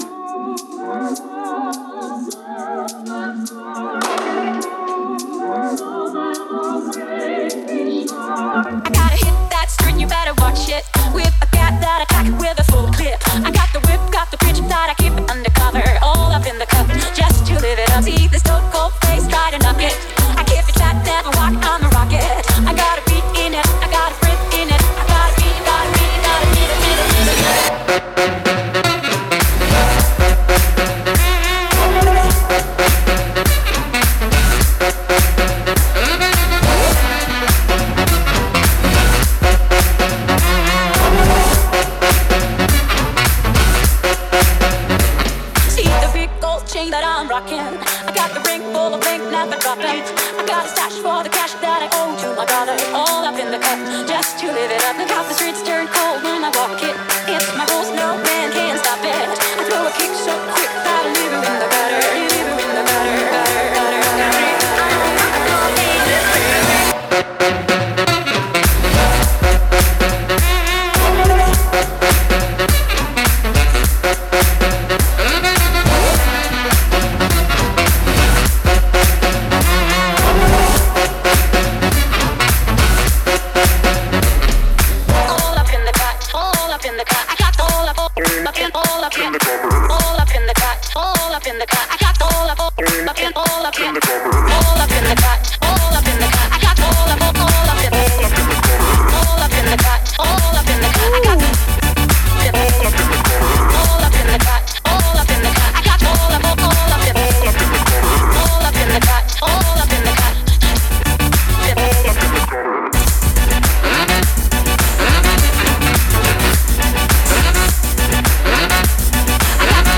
BPM104
MP3 QualityMusic Cut